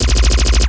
Alien Handgun
LASRGun_Alien Handgun Burst_06_SFRMS_SCIWPNS.wav